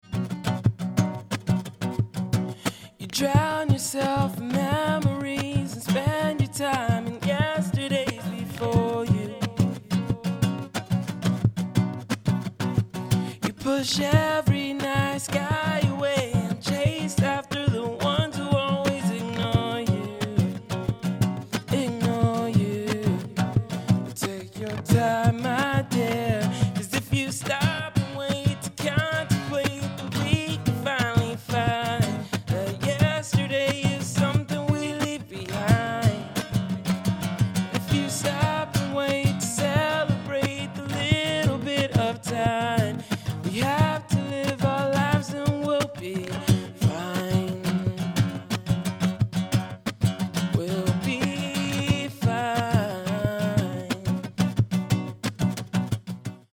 acoustic guitar
Tracking Demo Mix